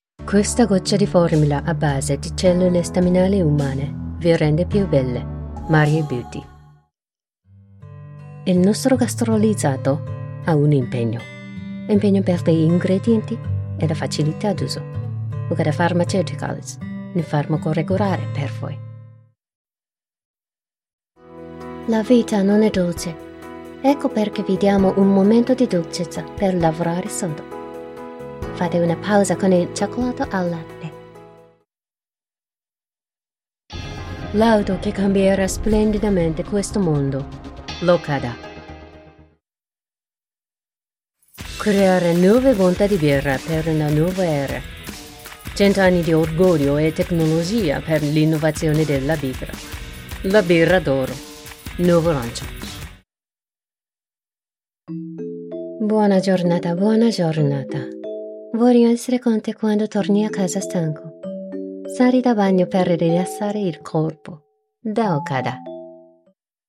Experienced
Trustworthy
Reliable